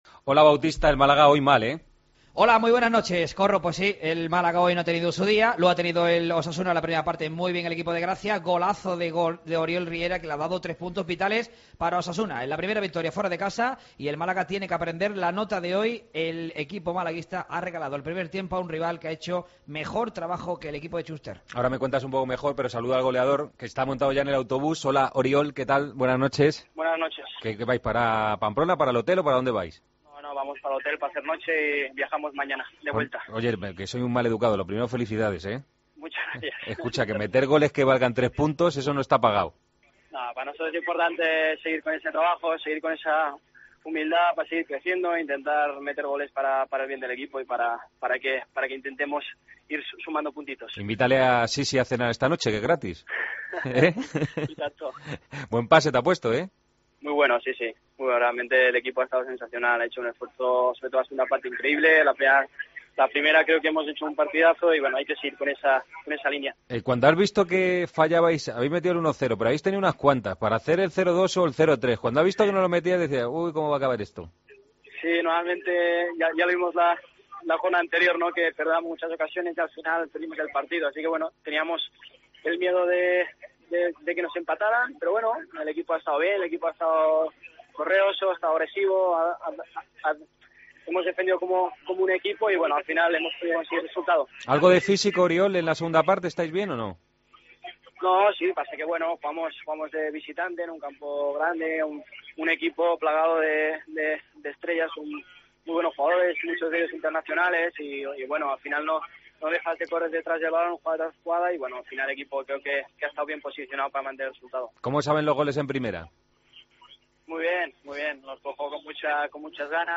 AUDIO: El jugador de Osasuna, autor del gol del triunfo ante el Málaga, valoró la primera victoria a domicilio del conjunto rojillo.